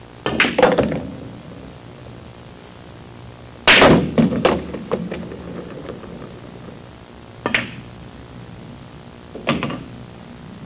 Playing Pool Sound Effect
Download a high-quality playing pool sound effect.
playing-pool-1.mp3